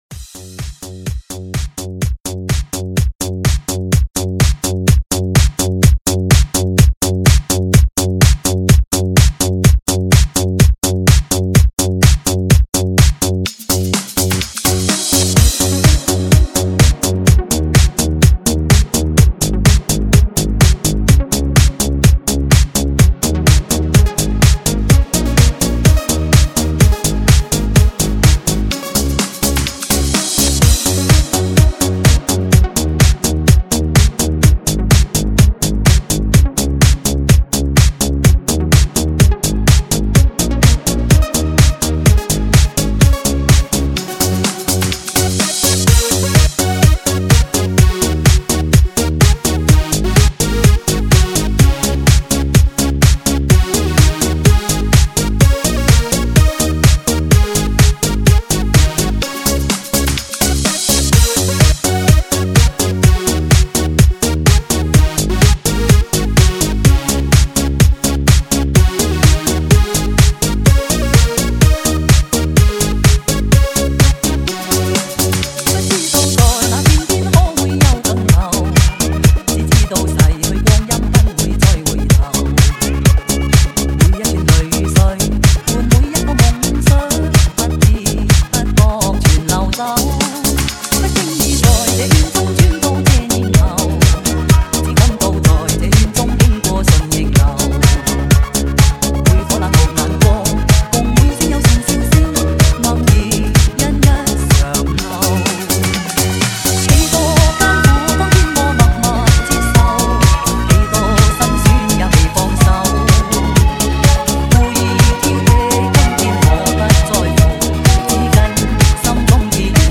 粤语老歌慢摇嗨曲
现场美女劲嗨热舞魅力十足 前卫节奏最嗨的享受,
引爆全场至高点最HIGH舞曲带来的激情旋涡!